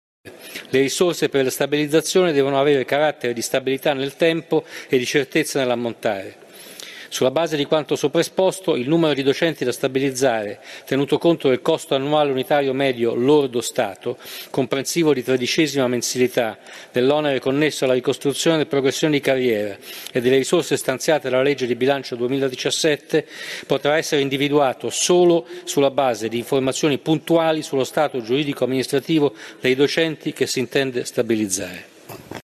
Lo ha affermato il ministro dell’Economia, Pier Carlo Padoan, durante il Question time alla Camera sulla stabilizzazione del personale del comparto scuola assunto con contratti a termine, anche alla luce di un recente pronunciamento della Corte di Cassazione.
Ascolta le parole del ministro Padoan